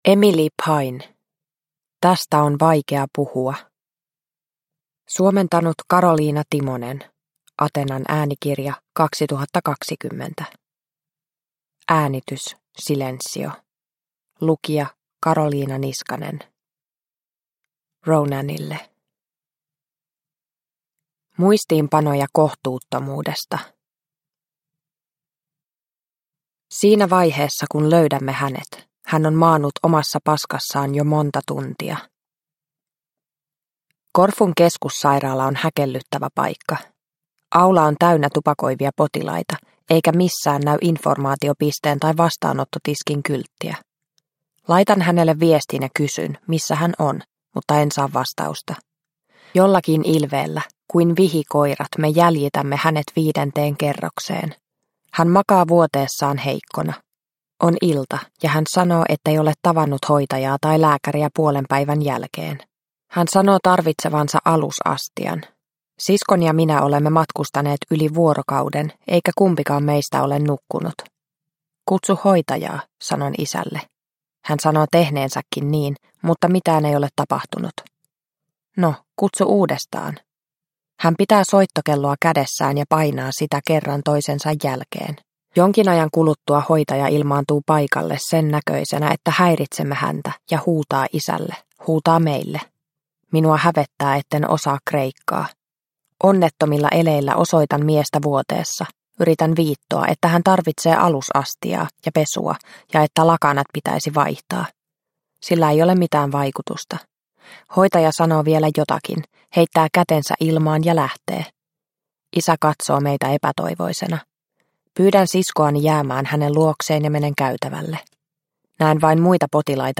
Tästä on vaikea puhua – Ljudbok – Laddas ner